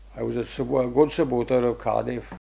the-phonology-of-rhondda-valleys-english.pdf